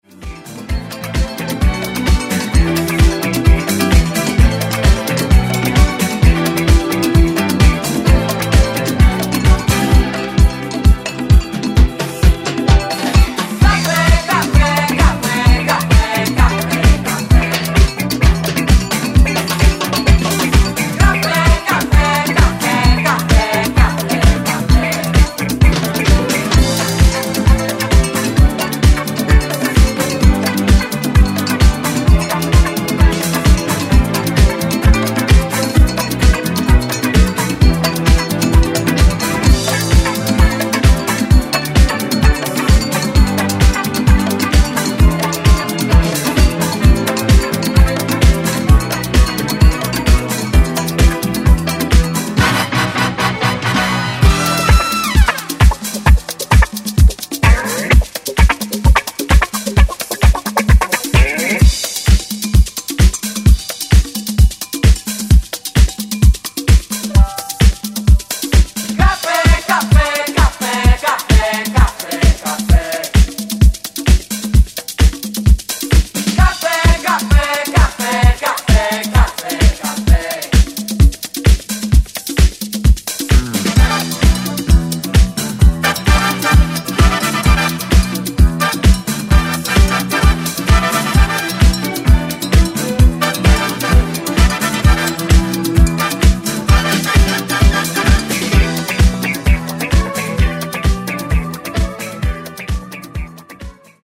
Genre: 70's
Clean BPM: 131 Time